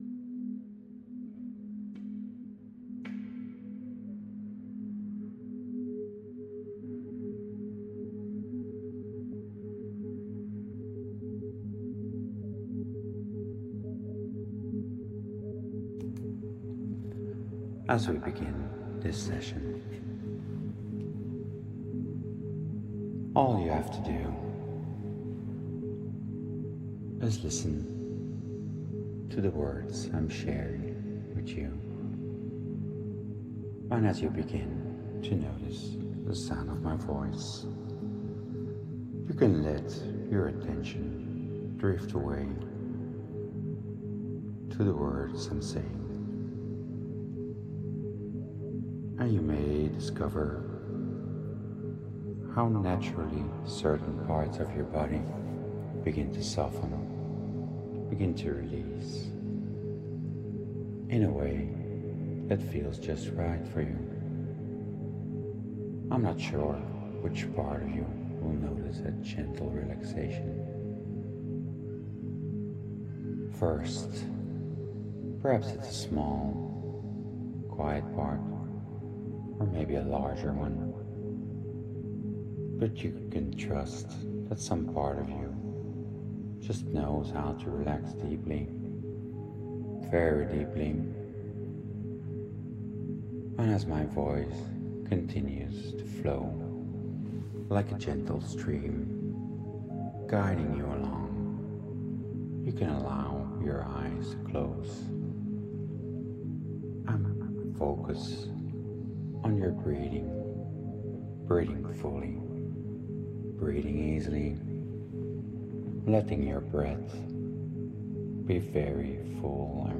Use headphones if you can. This is the opening session we give every new client — a slow, deliberate settling of the nervous system.
guided-hypnosis-master.m4a